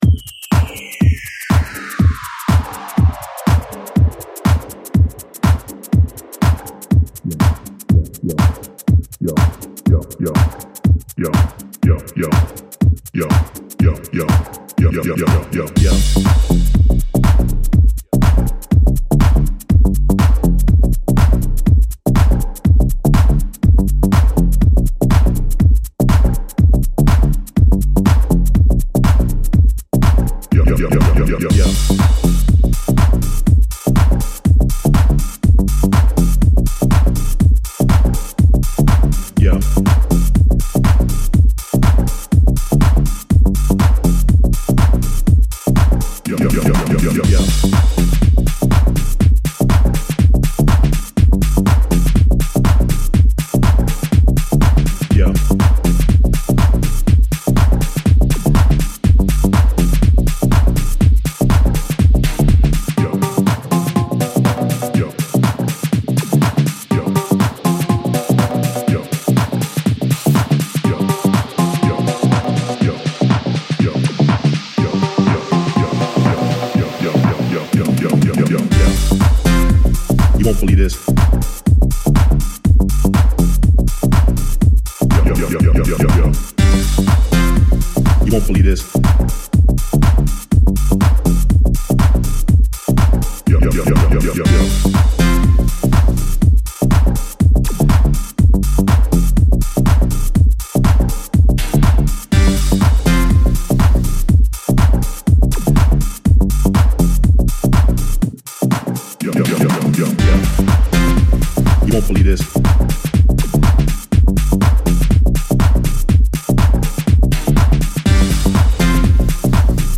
Tech House of the now!!